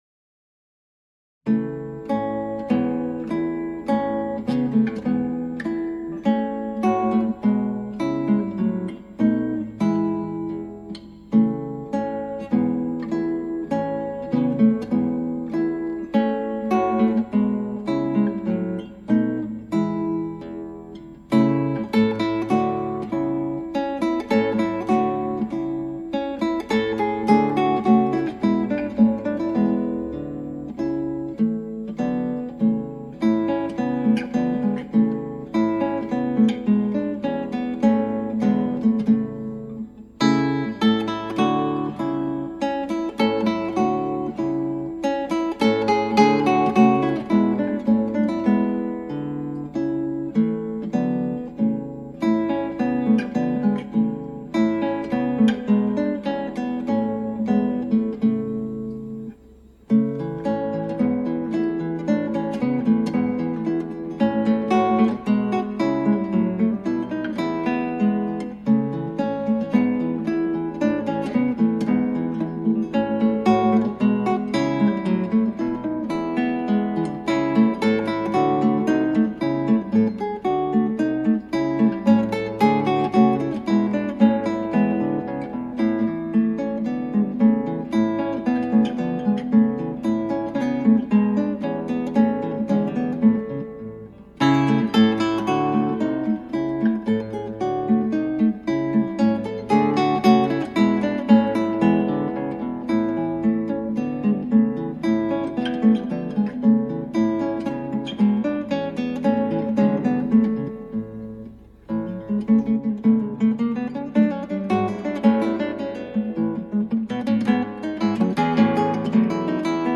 クラシックギター　ストリーミング　コンサート
バリエーション、残すところ一つを除いて録音しました。
う〜ん、５はやたら粘っこくなってしまいました。６は右手、苦手なパターンがあって苦戦してます、テンポ１３０はキツイっす。